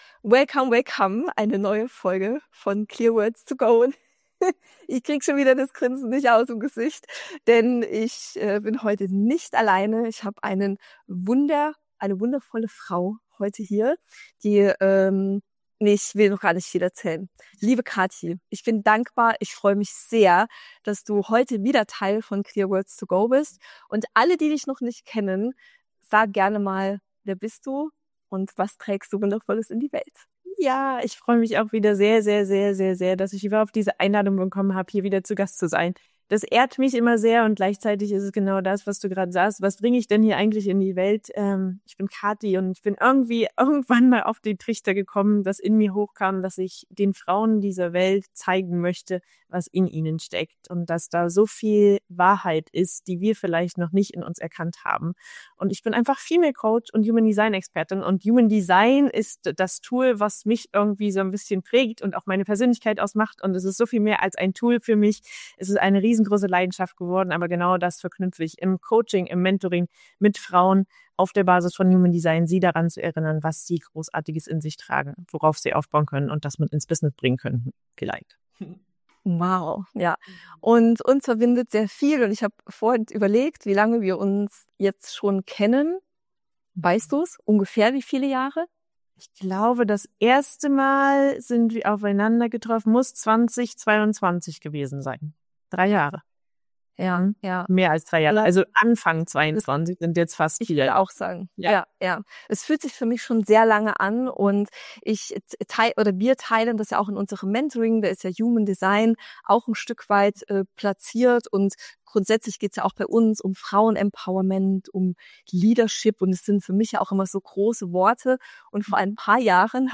#81 Über Weiblichkeit, Mut, Vertrauen & Kontrolle: Interview